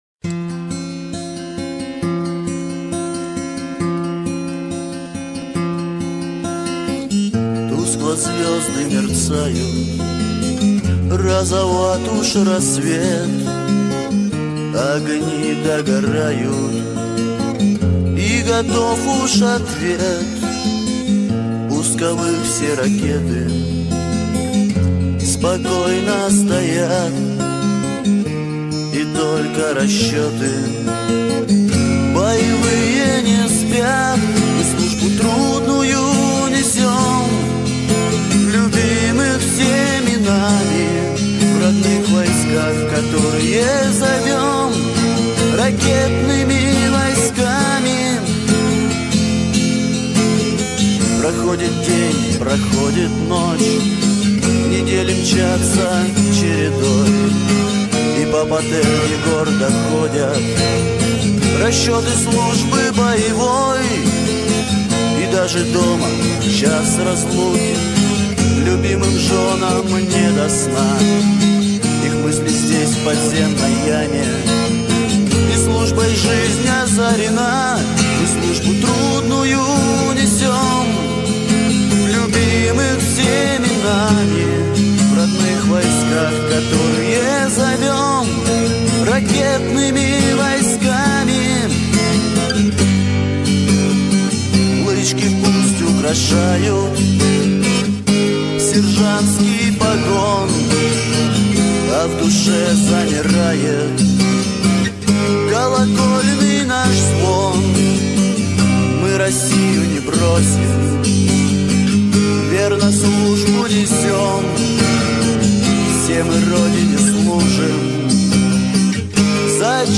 Армейская песня